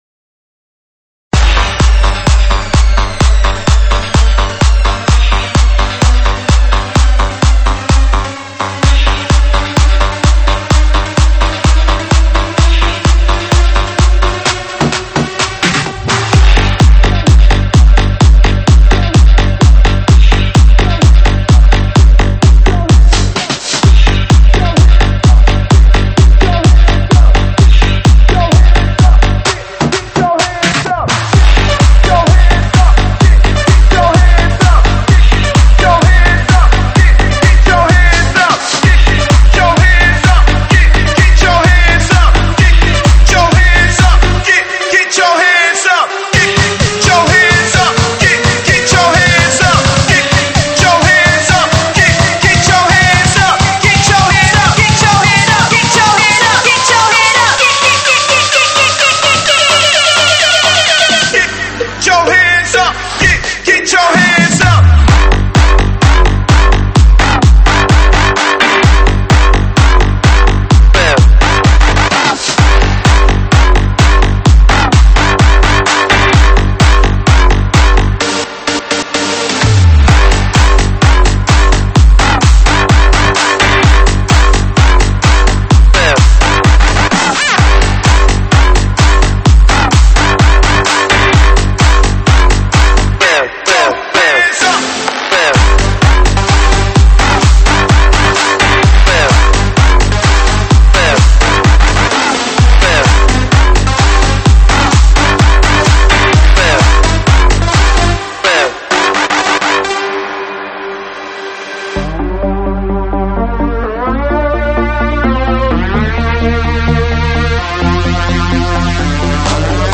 慢摇舞曲
舞曲类别：慢摇舞曲